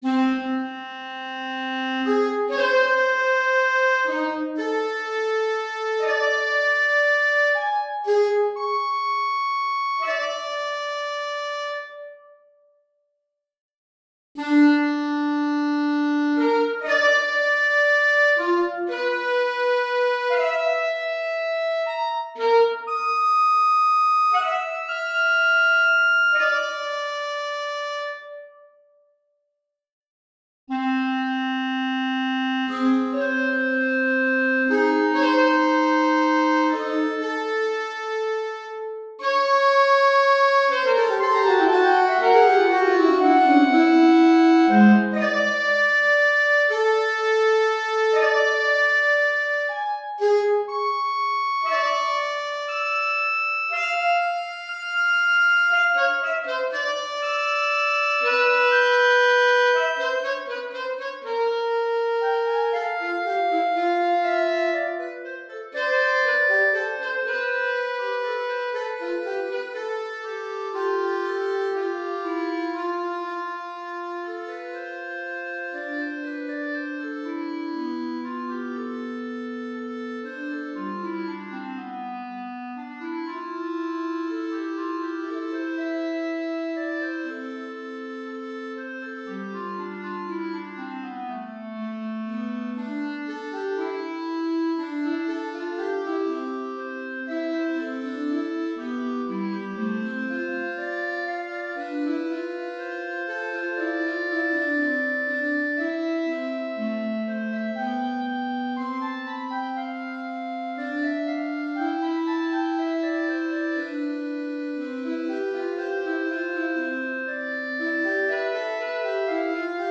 Chamber
Bb Clarinet and Alto Saxophone